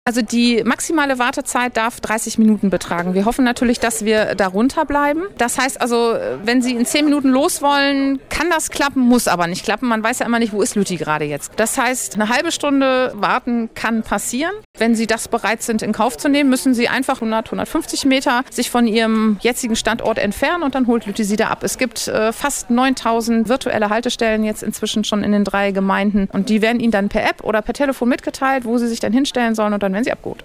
Landrätin Anna Kebschull erklärt, wie die Abholung mit dem Lütti Bus funktioniert.